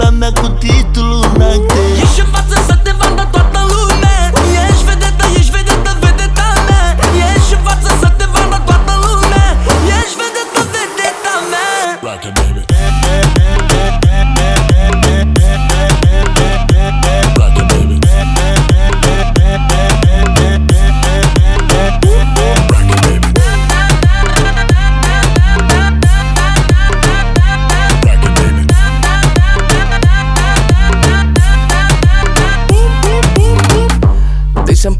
Рингтоны
Жанр: Поп